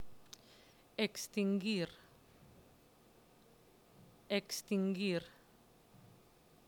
Escribe junto a las que no lo necesiten la palabra NO. Vas a escuchar cada palabra dos veces.